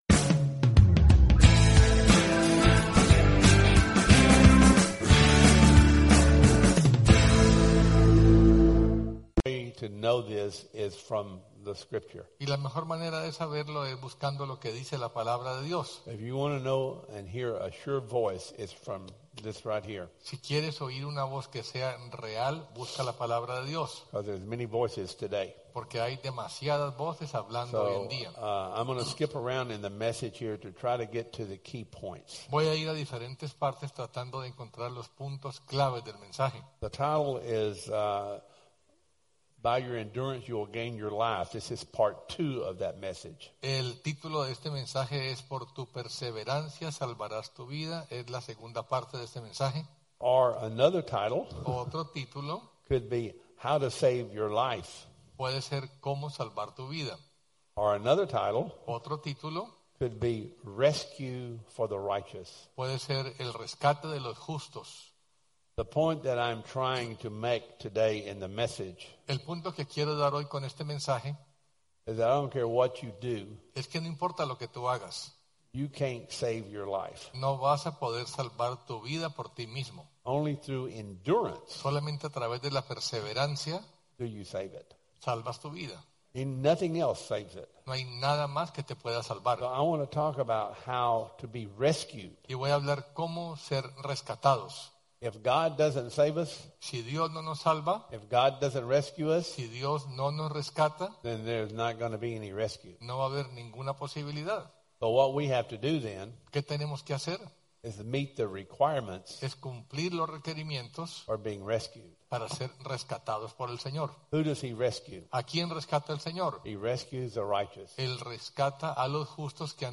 Sunday Services 16-20 Service Type: Sunday Service « By Your Endurance